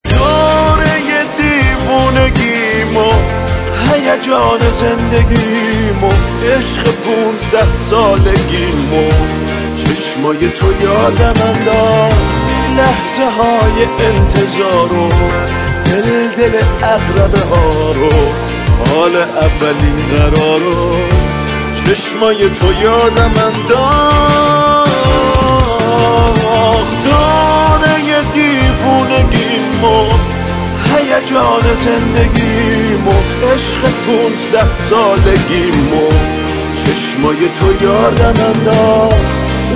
دسته : الکترونیک